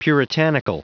Prononciation du mot puritanical en anglais (fichier audio)
Prononciation du mot : puritanical
puritanical.wav